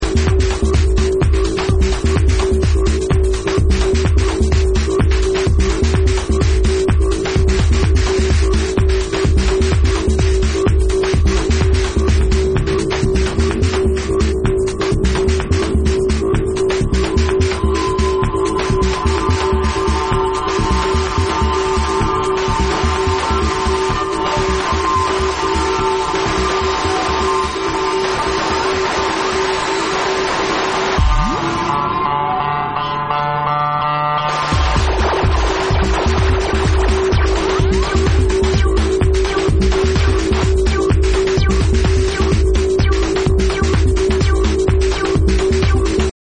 Minimal techno anthem